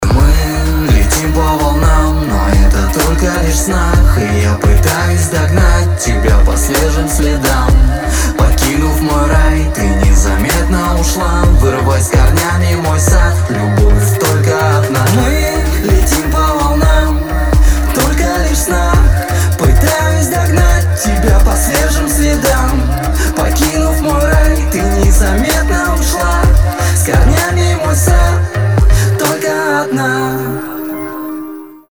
красивые
лирика
Хип-хоп